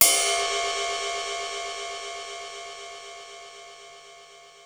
Index of /kb6/Korg_05R-W/Korg Cymbals
Ride Cym 01 X5.wav